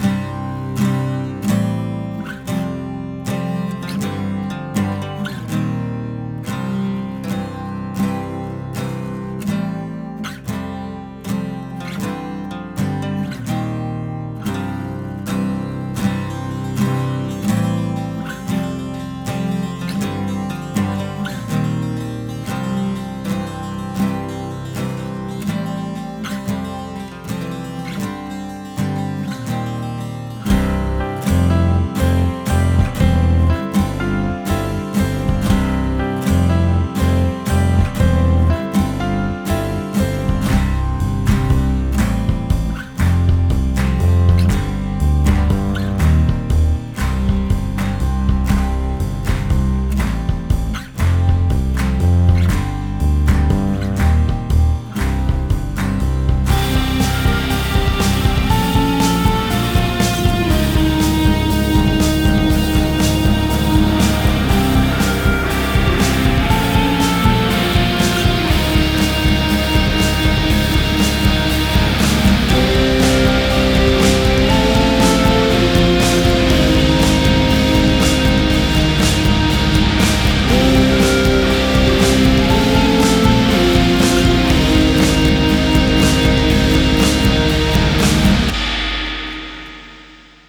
acoustic guitar 02.wav